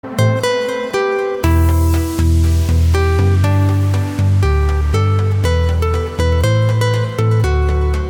• Качество: 192, Stereo
красивые
мелодичные
спокойные
без слов
цикличный
поставь и зацени 8-ми секундный цикл